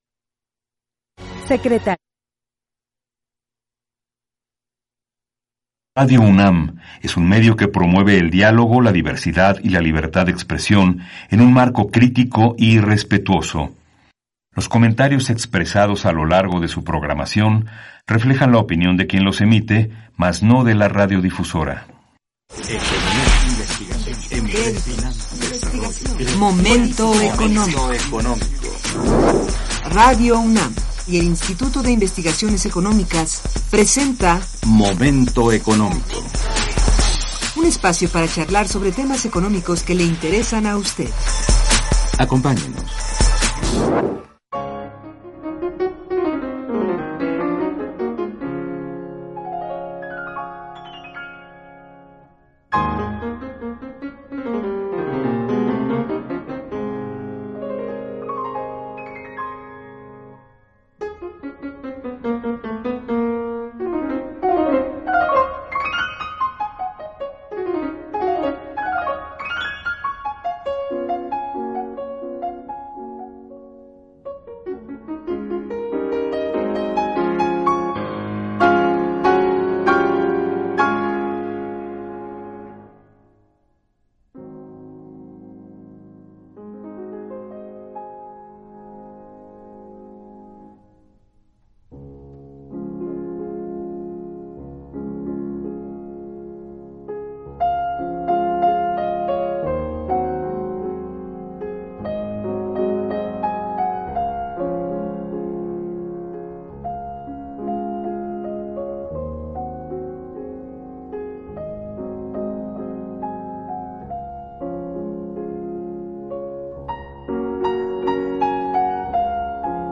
Programa de Radio Momento Económico